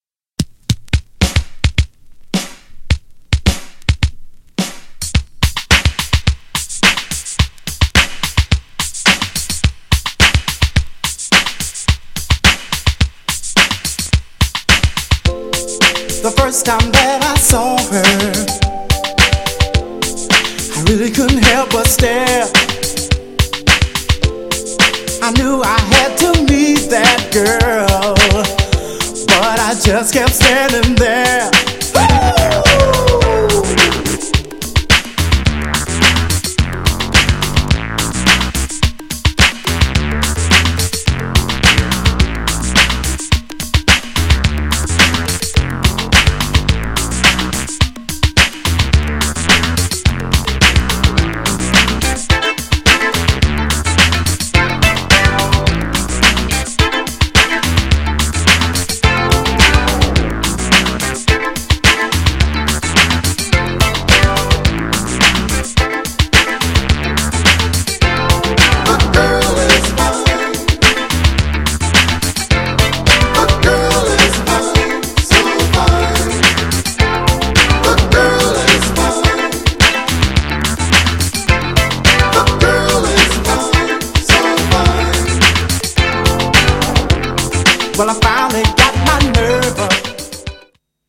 83年リリースなだけあってけっこうエレクトリックだけどメロディアス。
GENRE Dance Classic
BPM 106〜110BPM
DUBがカッコイイ
エレクトロ # シンセ # ダビー # フルート